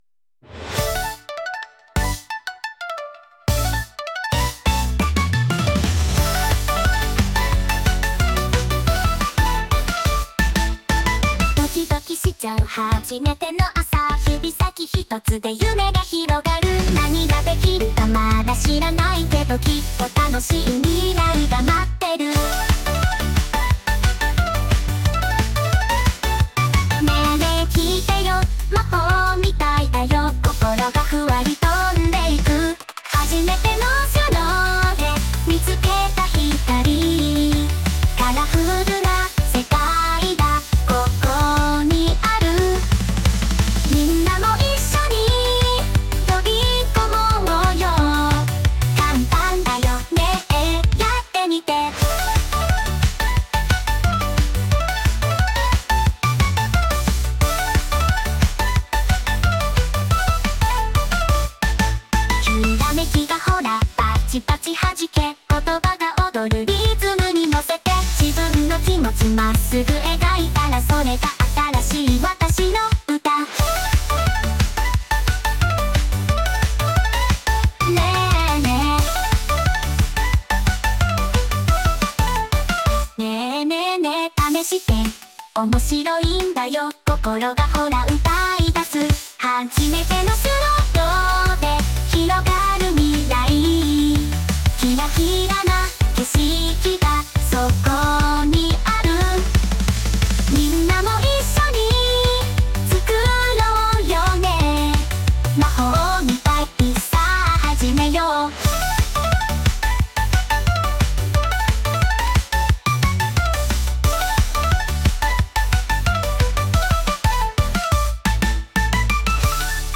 「アニメのオープニングみたいな、元気で楽しい可愛い感じの曲。」と入力してみました。
しかもこれ、全部AIが自動でメロディもアレンジも付けて歌ってくれたんですよ！